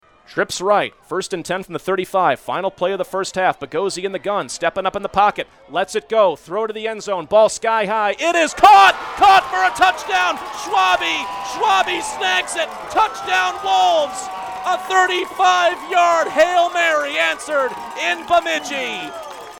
as heard on Fox Sports Aberdeen: